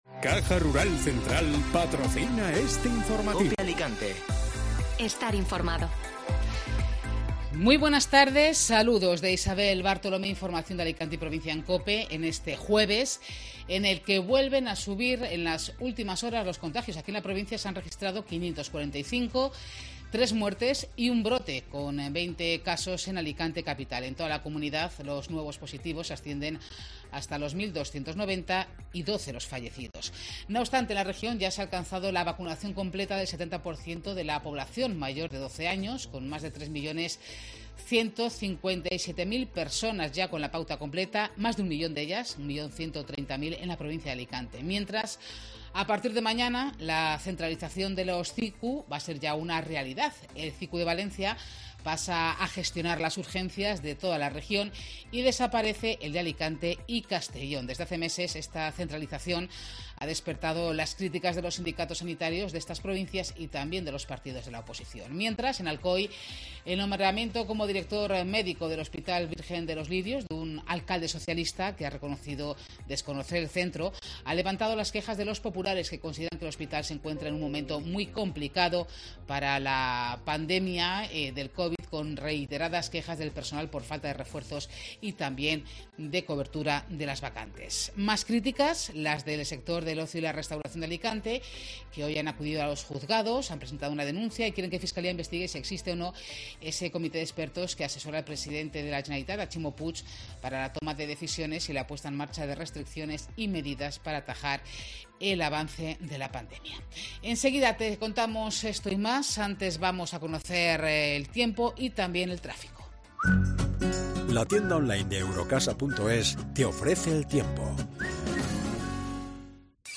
AUDIO: Escucha las noticias de este jueves en Alicante. El Postiguet recibe la bandera 'Q' de calidad turística.